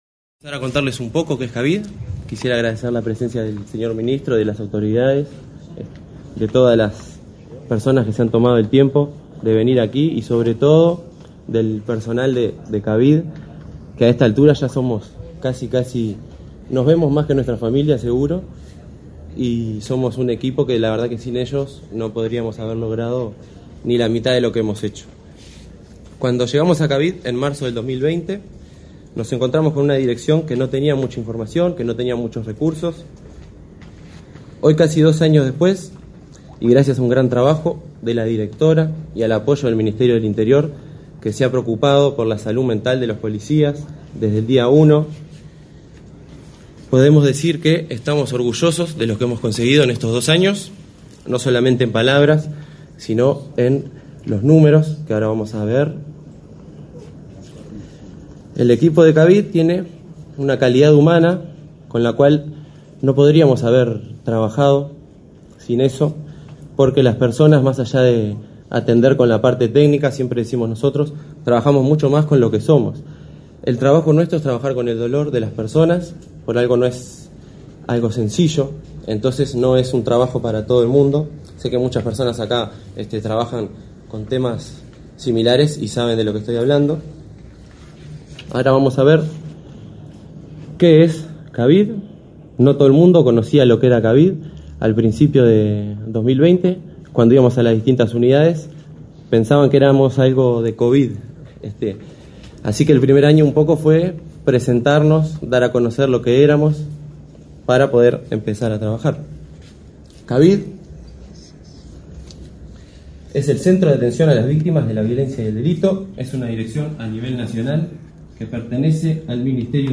Conferencia de prensa por rendición de cuentas del Centro de Atención a las Víctimas de la Violencia y el Delito